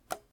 buttonout01.ogg